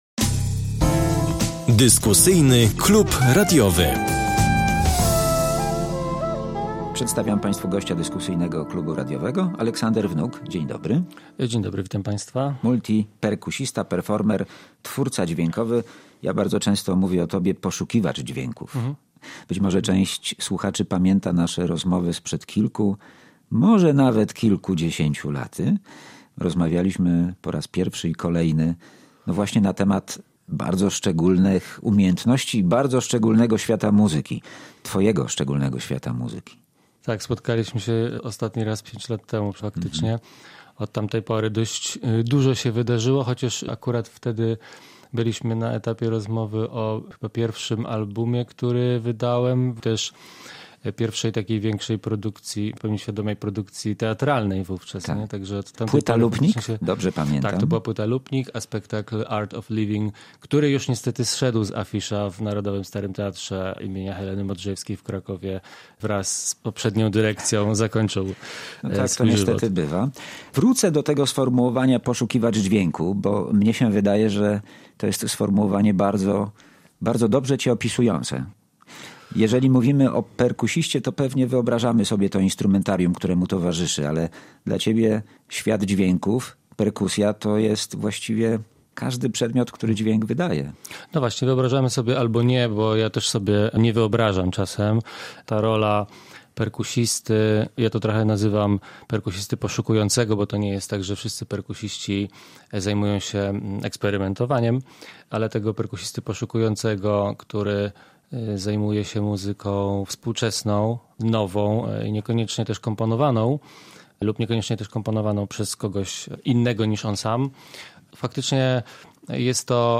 Fragmenty muzyki